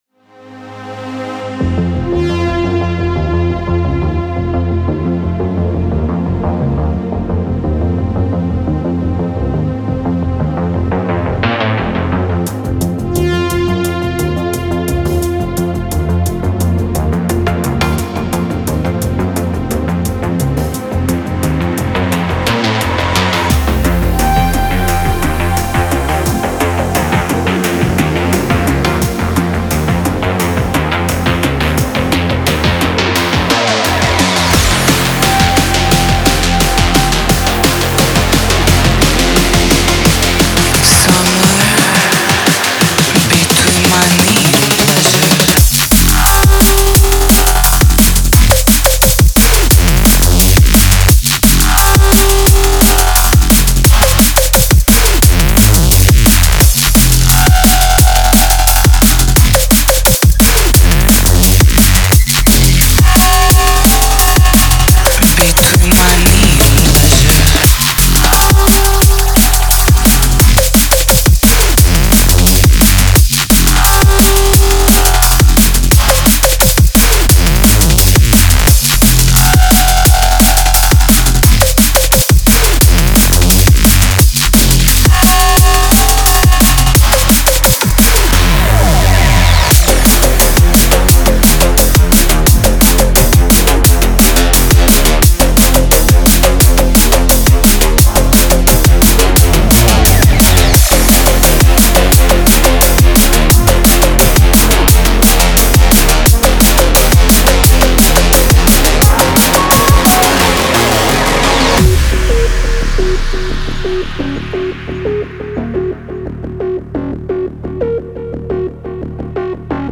Drum & Bass and Bassline